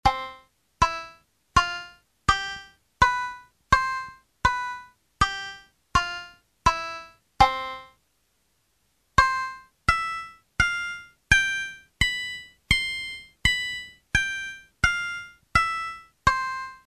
scale1_2.mp3